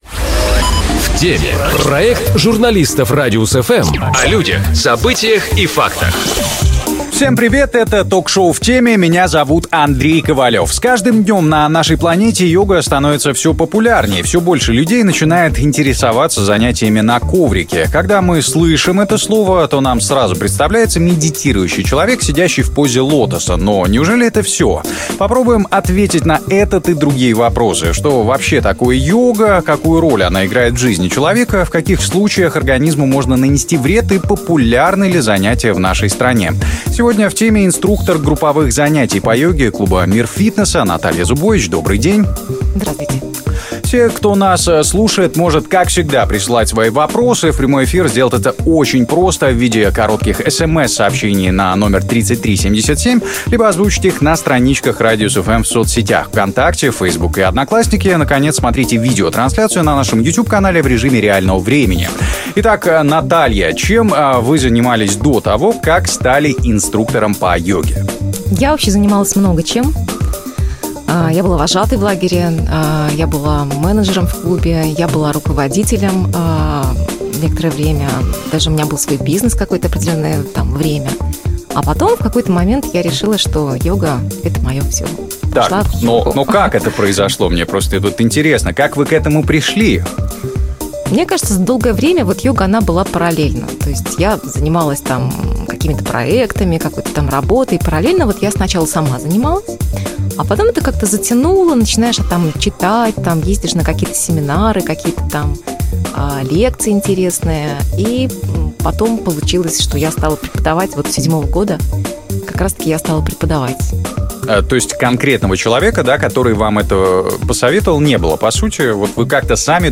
Попробуем ответить на этот и другие вопросы: что вообще такое йога, какую роль она играет в жизни человека, в каких случаях организму можно нанести вред и популярны ли занятия в Беларуси? У нас в гостях инструктор групповых занятий по йоге